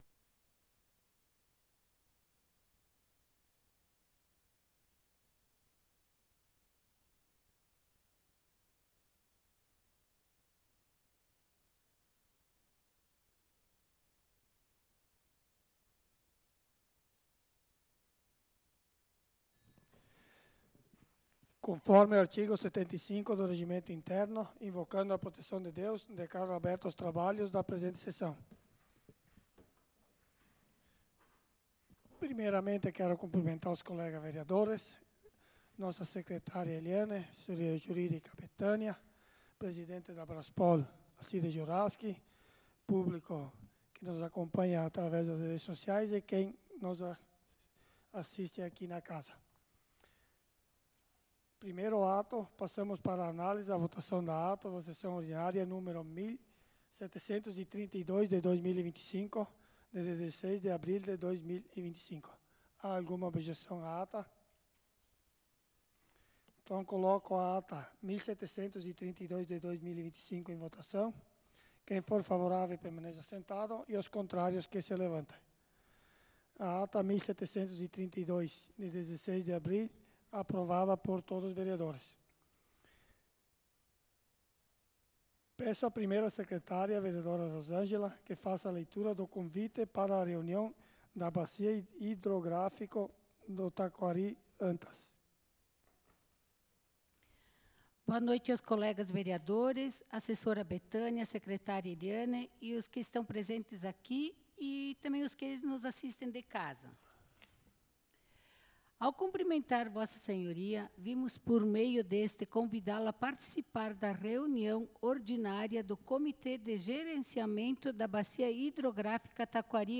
Sessão Ordinária do dia 23/04/2025